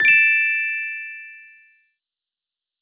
chime.wav